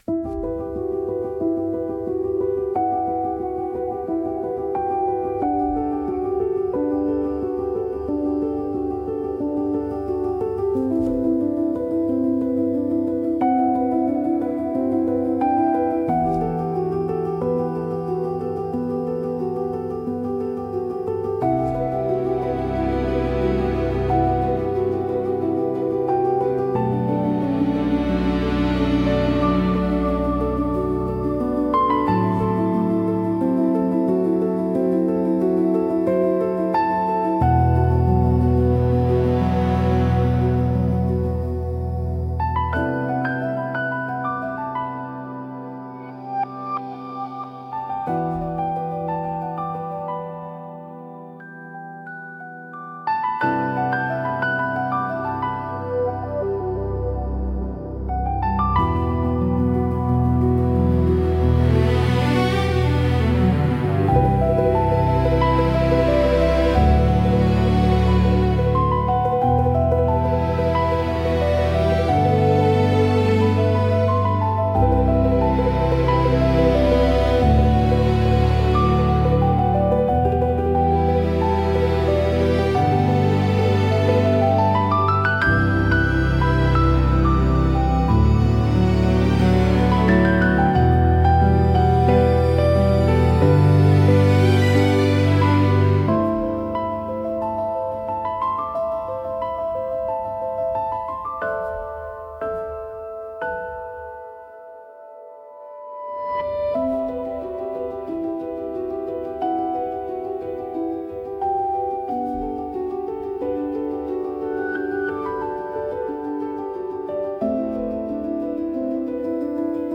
Sound Design-Audio Assets